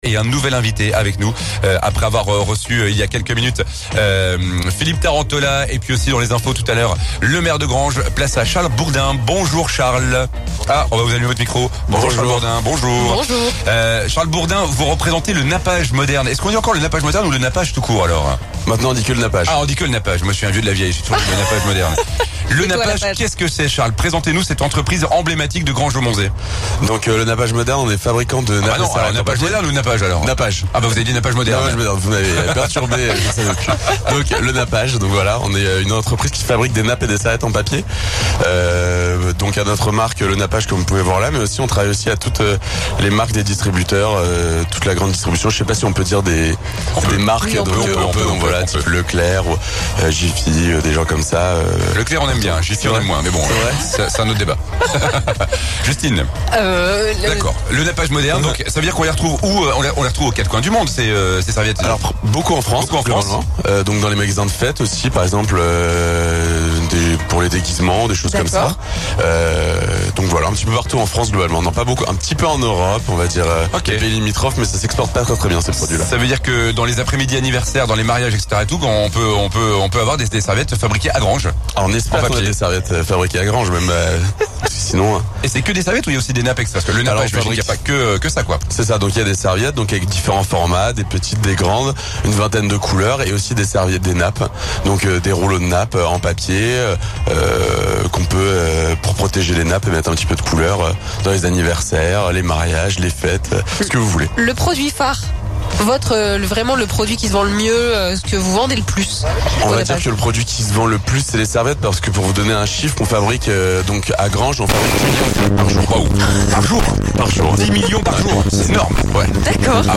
Le Nappage à Granges-Aumontzey : le fabricant de serviettes en papier était en direct sur Vosges FM dans le Tour Bus
Pendant notre arrêt du Tour Bus Vosges FM à Granges-Aumontzey, nous avons accueilli à l'antenne "Le Nappage", entreprise créée en 1945 et qui opère depuis dans la commune. L'été dernier, elle a été repris par une famille qui souhaite faire perdurer le savoir-faire de la production de nappes et serviettes jetables.